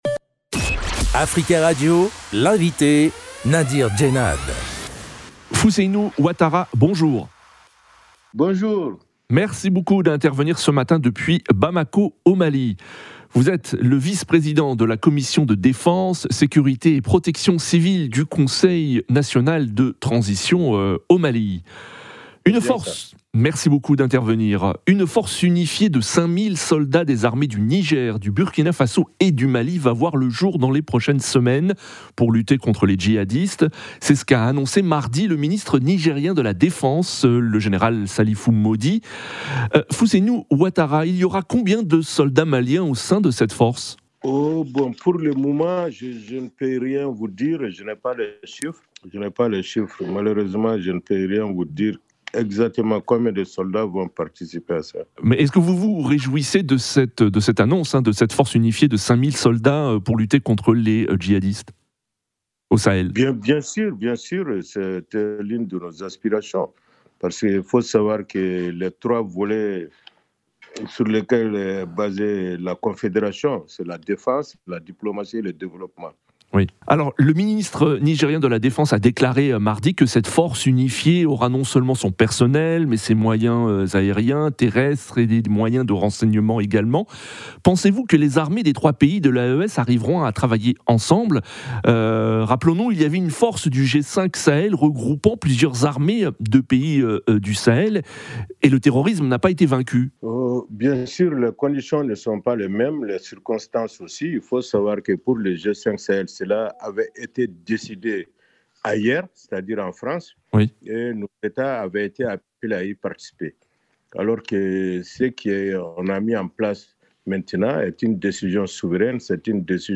Fousseynou Ouattara est le vice-président de la Commission de Défense, sécurité et protection civile du Conseil national de transition au Mali. Il était l'invité d'Africa Radio jeudi 23 janvier 2025 à 07h45.